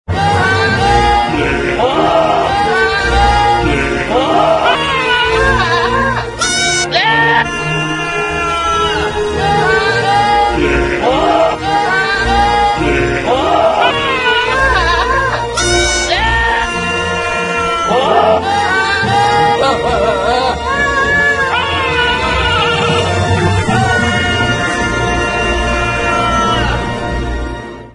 Cine y Televisión